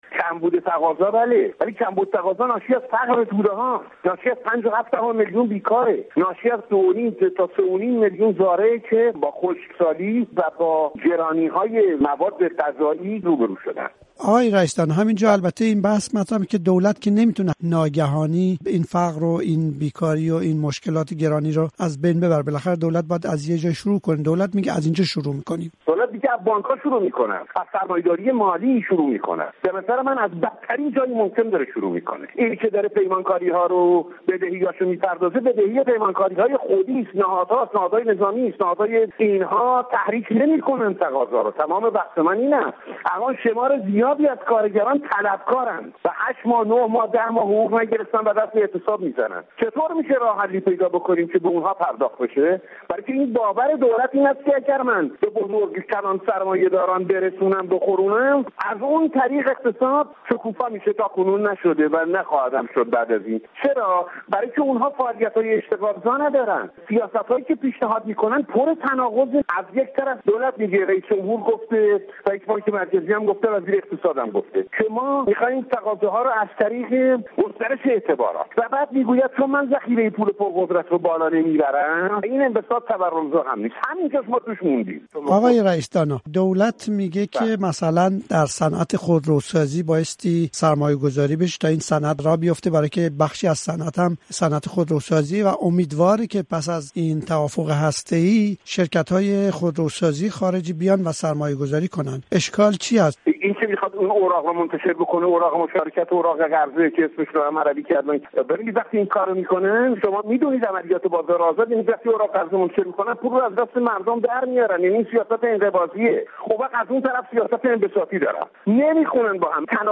سیاست اقتصادی دولت برای خروج از رکود: گفت‌وگوی